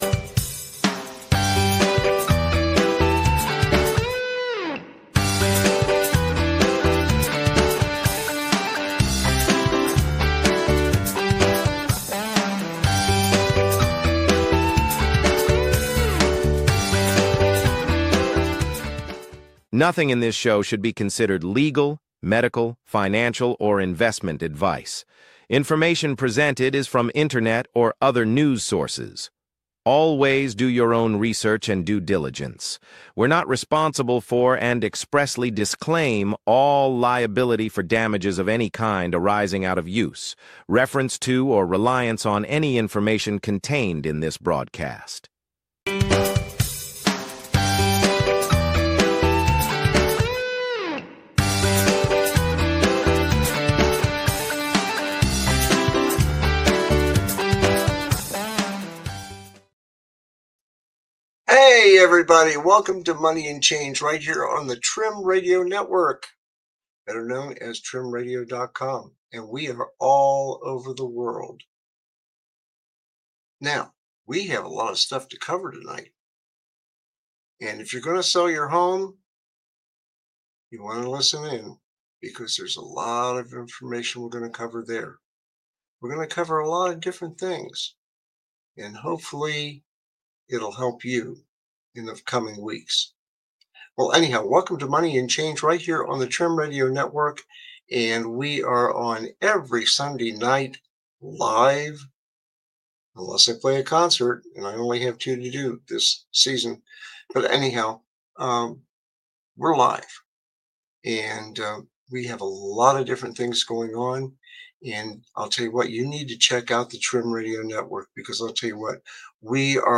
Join us live every Sunday at 7 PM ET as we tackle critical financial news, market trends, and practical advice to help you navigate these uncertain times. This week, we're diving deep into the housing market, government programs, and the changing landscape of work and money.